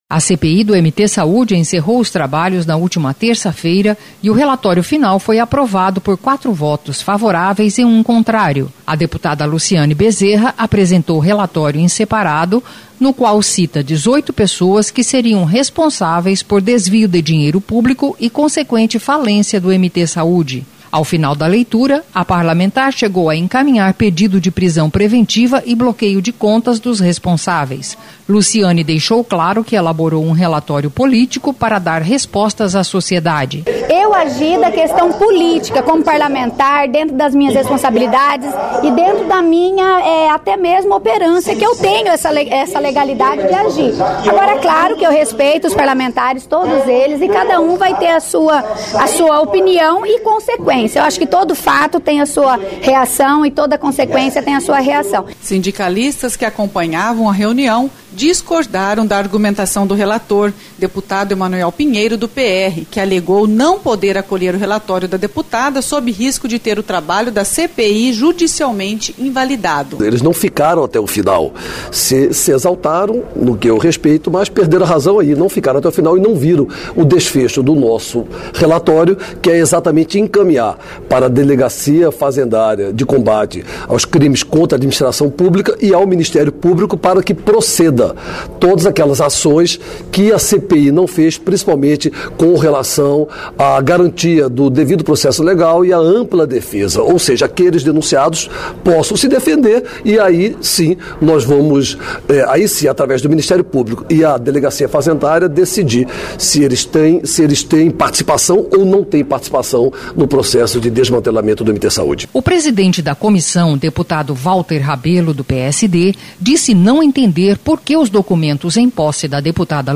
Boletim da Rádio Assembleia